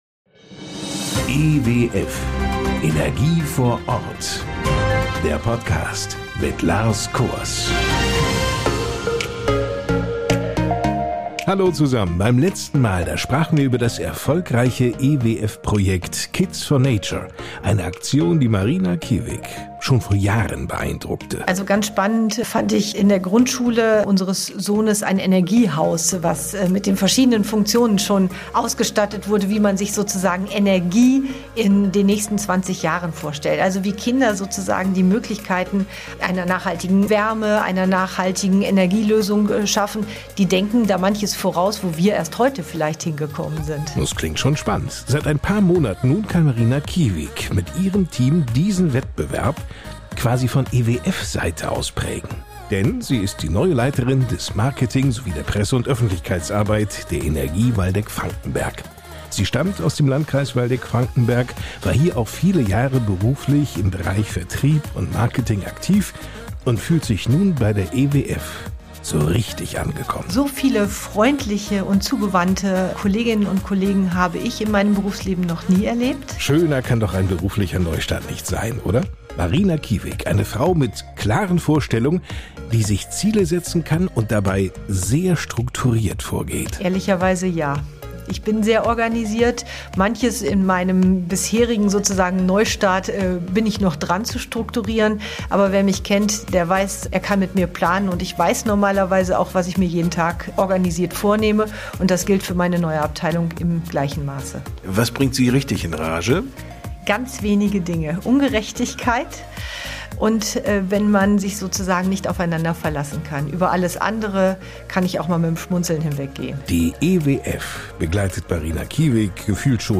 Interviews mit Experten aus der Branche, die Ihnen Einblicke in